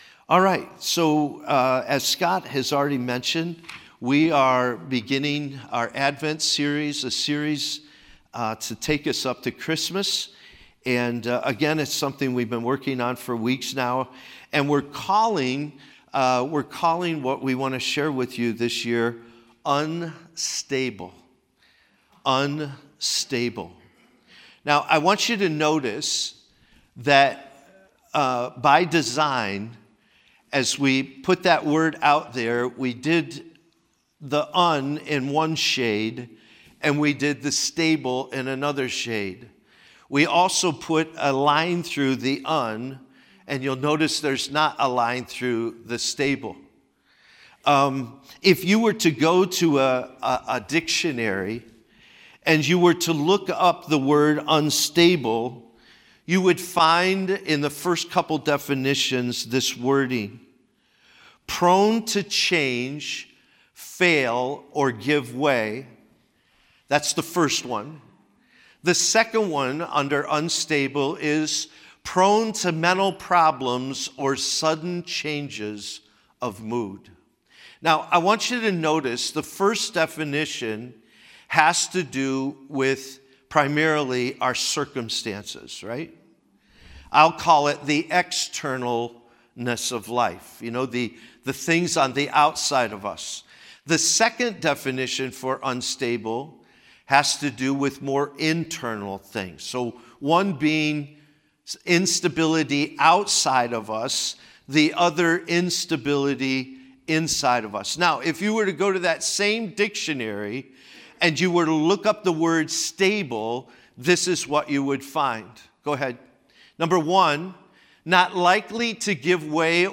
We’re kicking off a new sermon series entitled UNSTABLE.